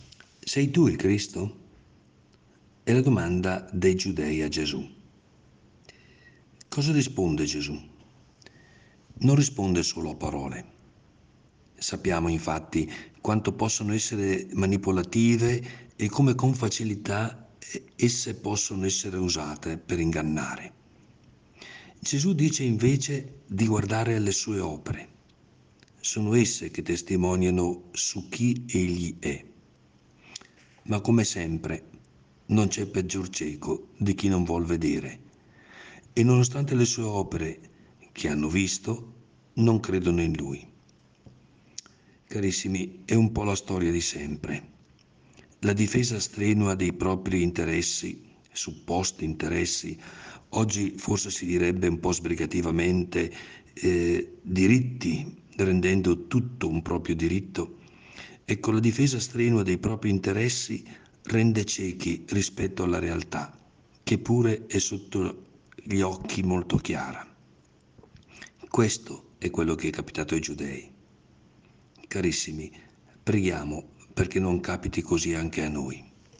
PODCAST – Prosegue la rubrica podcast “In ascolto della Parola”, curata da Mons. Carlo Bresciani, vescovo della Diocesi di San Benedetto del Tronto – Ripatransone – Montalto, il quale ci accompagnerà con un contributo quotidiano.
Il Vescovo commenta la Parola di Dio per trarne ispirazione per la giornata.